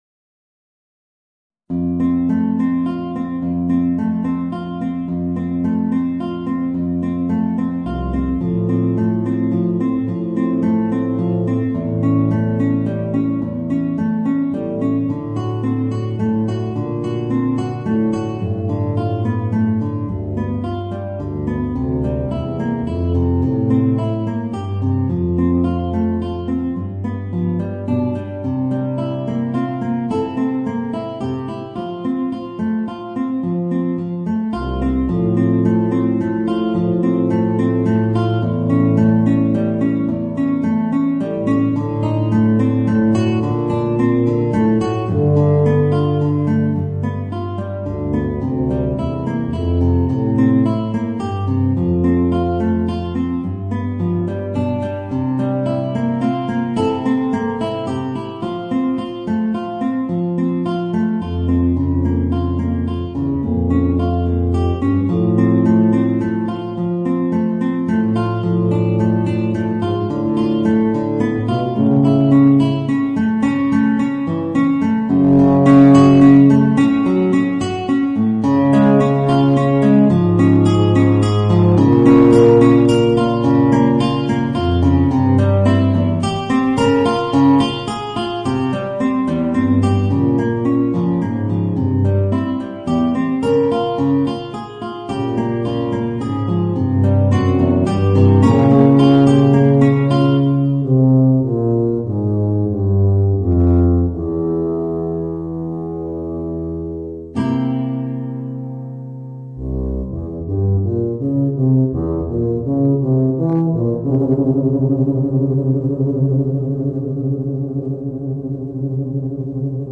Voicing: Bb Bass and Guitar